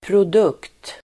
Uttal: [prod'uk:t]